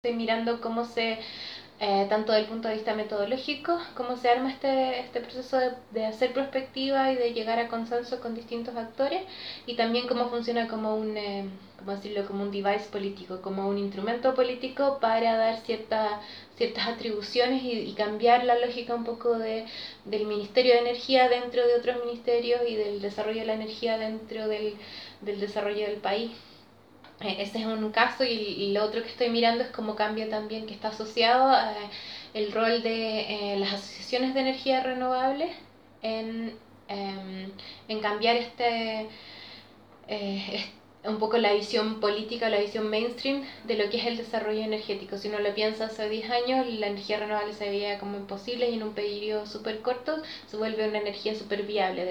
En esta entrevista, la investigadora detalla acerca de su trabajo en el desarrollo de políticas energéticas en Chile y cómo complementa su doctorado de cambio tecnológico e innovación, en esta nueva área de estudio.